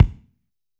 B.B KICK 5.wav